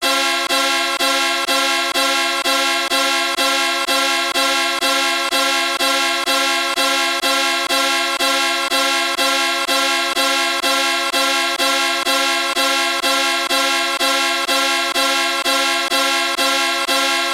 【効果音】車のセキュリティアラーム - ポケットサウンド - フリー効果音素材・BGMダウンロード